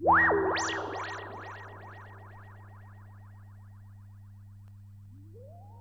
Scary FX.wav